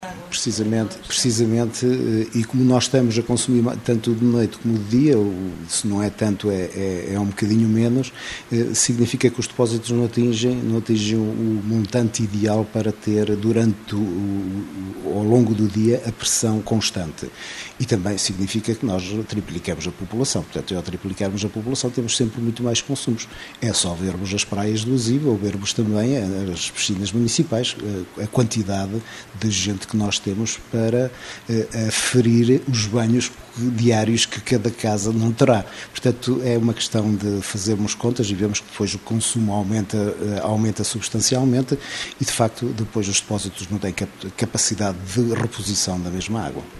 O presidente da Câmara acrescenta ainda que esta situação acontece no verão por ser a época do ano em que a população do concelho aumenta, e, por consequência, aumenta o consumo de água.